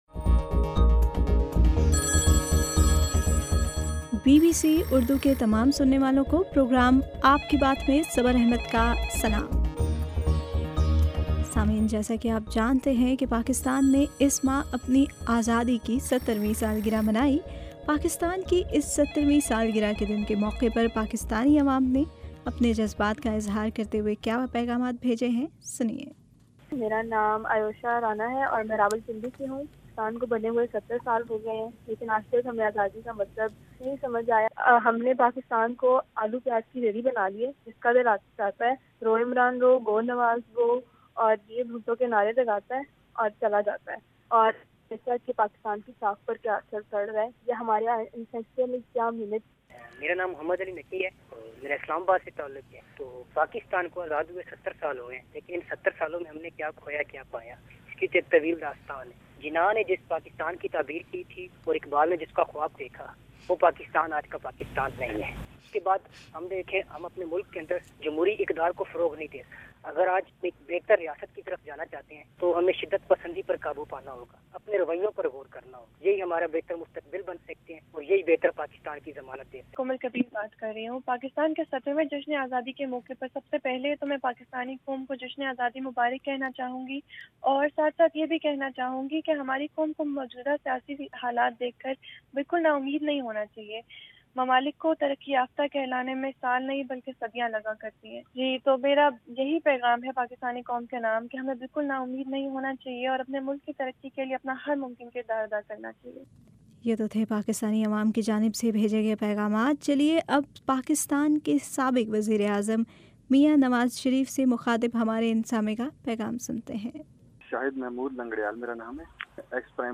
آپ کے صوتی پیغامات پر ، مبنی پروگرام ” آپ کی بات ٌ